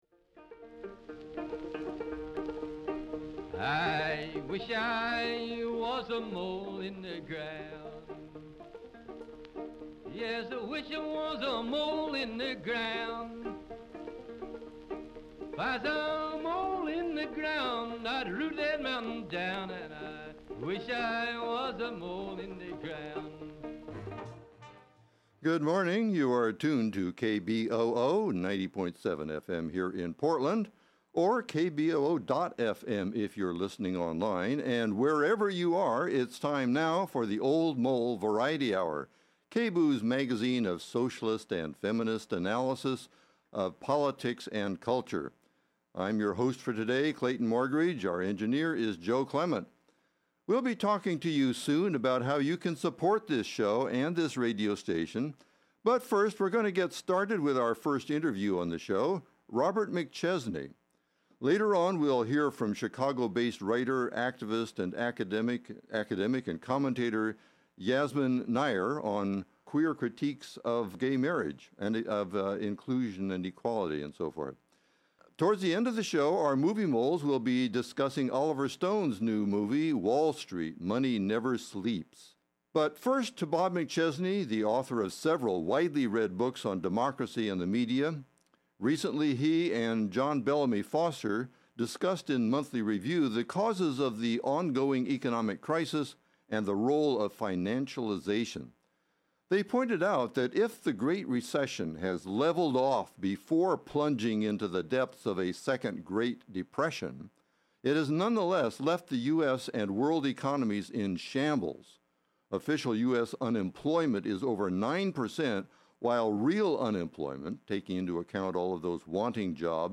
Many thanks to all those who called during the show with pledges of support; we made our goal for the show.